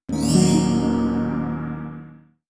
magic-string-spell-2.wav